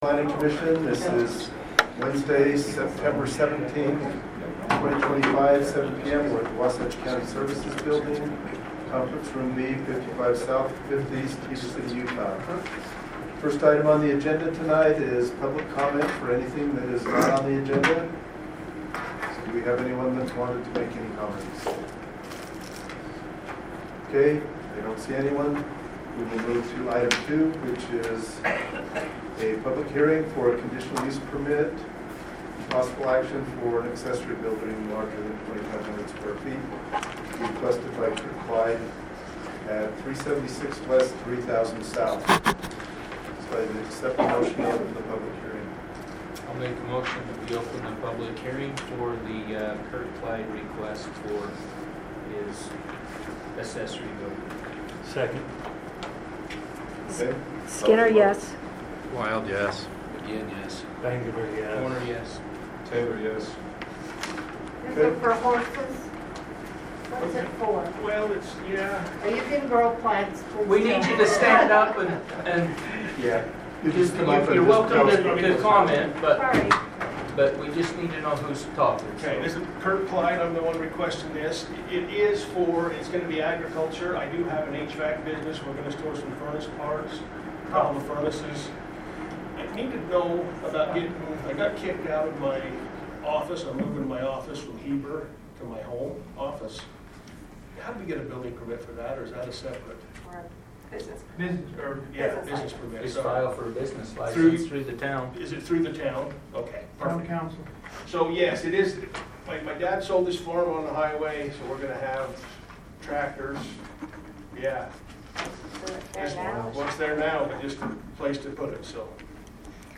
September 17, 2025 Planning Commission Meeting Audio